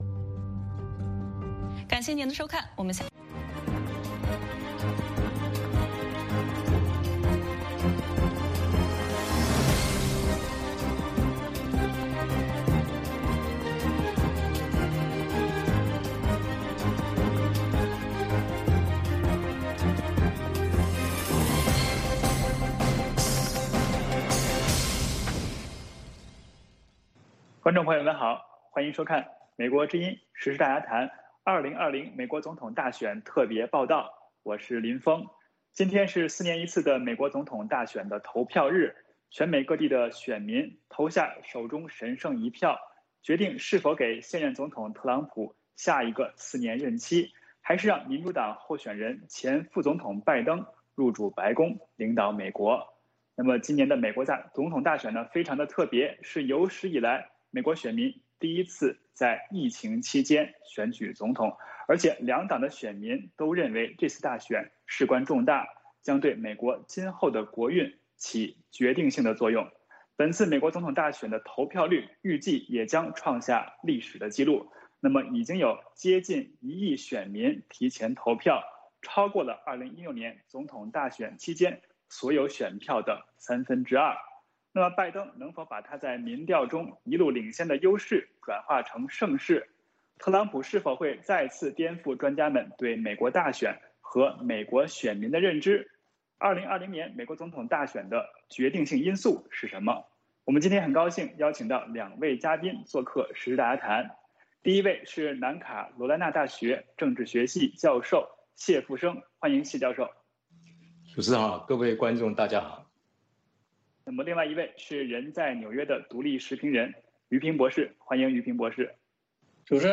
时事大家谈(2020年11月3日) 2020美国总统大选谁会是赢家？ 嘉宾：